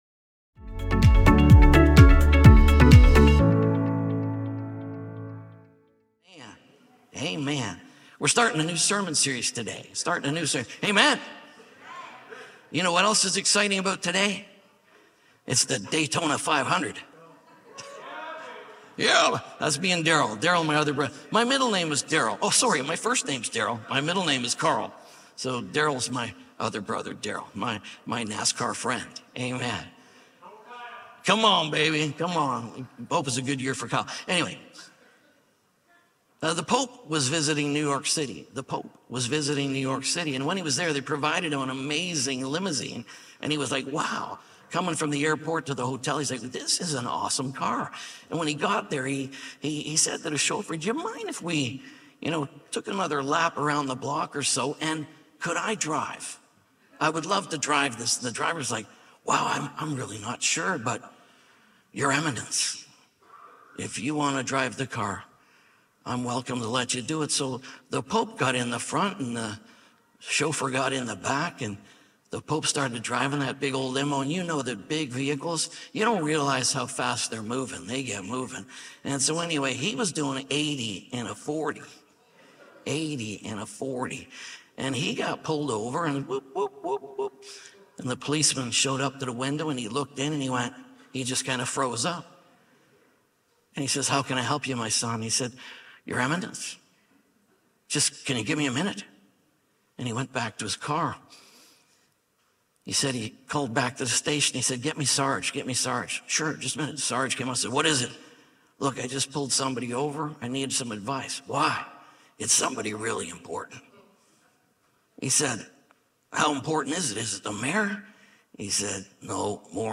Us And God | Us Series | Sermon Only.mp3